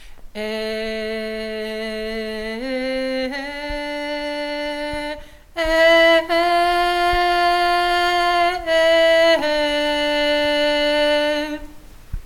Voix Basse Mp 3